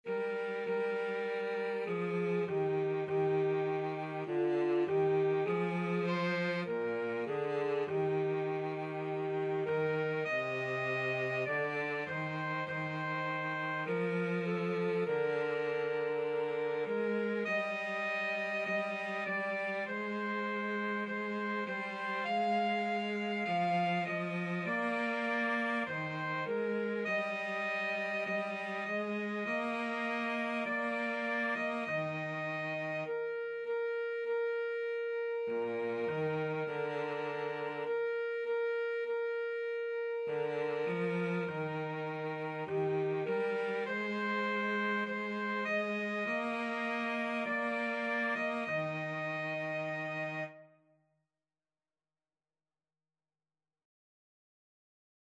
Christian
Alto SaxophoneCello
4/4 (View more 4/4 Music)